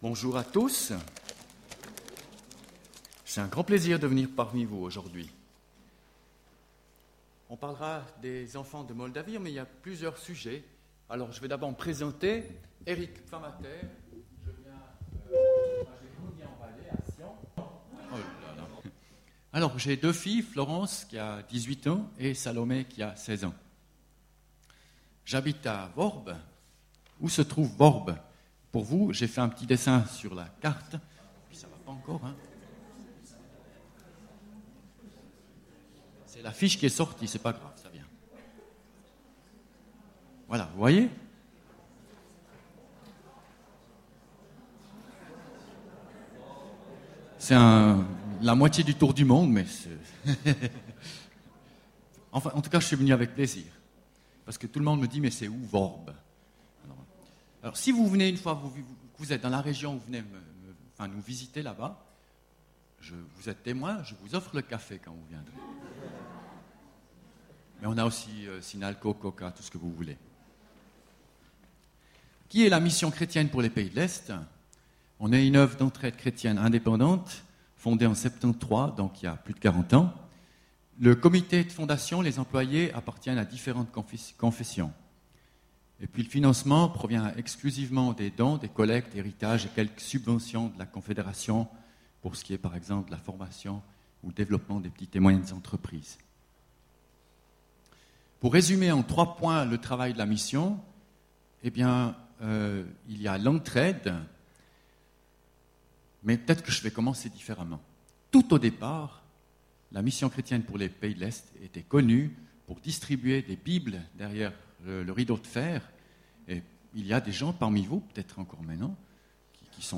Culte du 11 octobre 2015